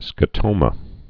(skə-tōmə)